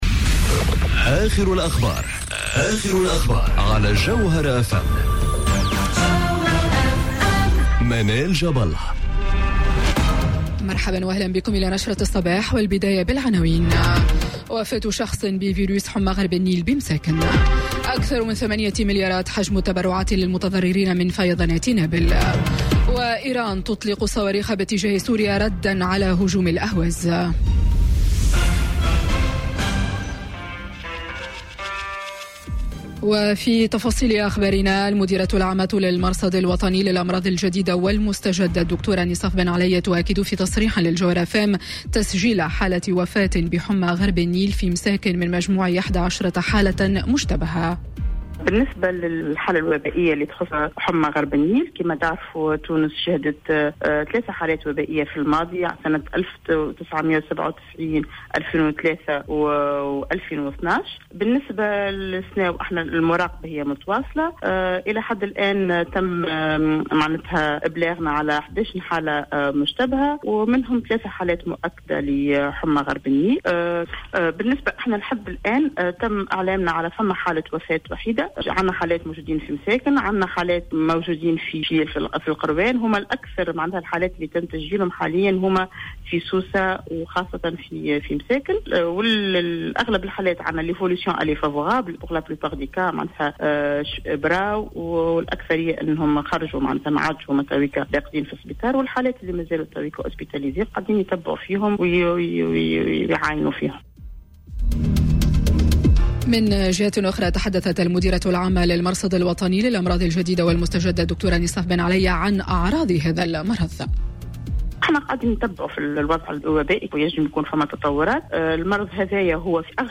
نشرة أخبار السابعة صباحا ليوم الإثنين 01 أكتوبر 2018